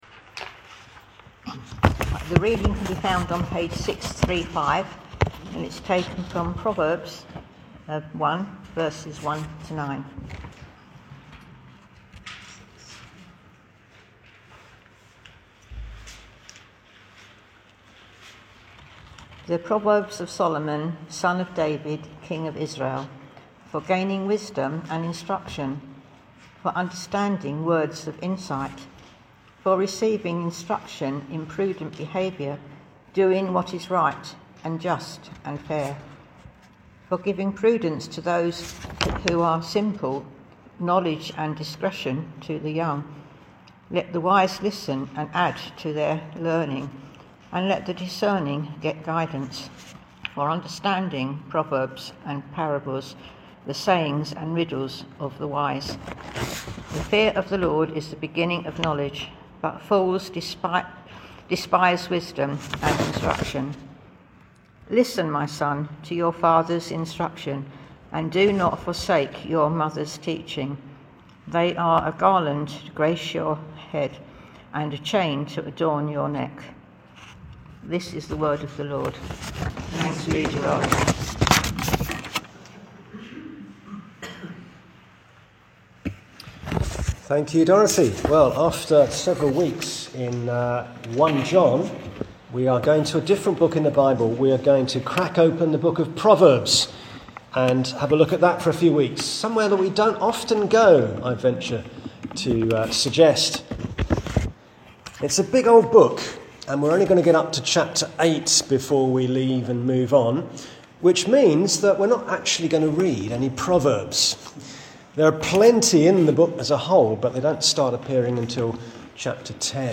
Passage: Proverbs 1:1-9 Service Type: Thursday 9.30am